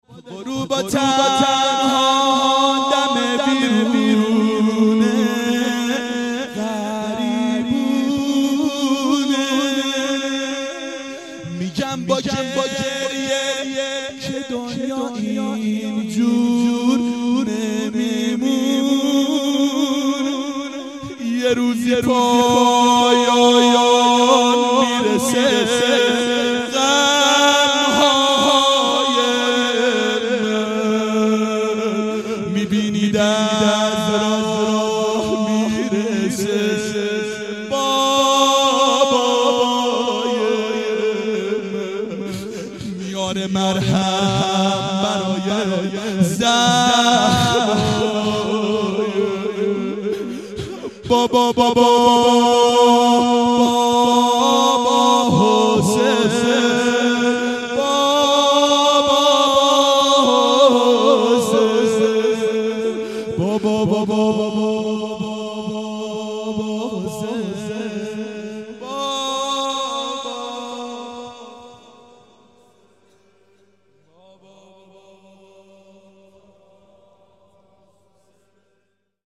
• دهه اول صفر سال 1391 هیئت شیفتگان حضرت رقیه سلام الله علیها (شب شهادت)